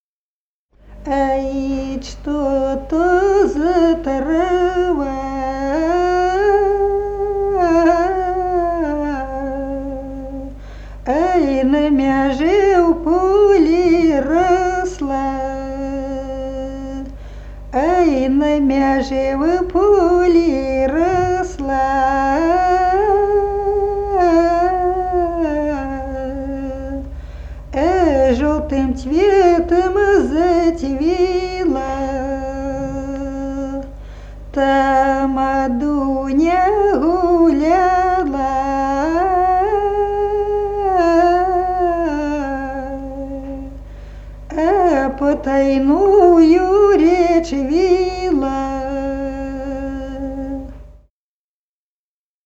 Народные песни Смоленской области
«Ай, и что то за трава» (баллада).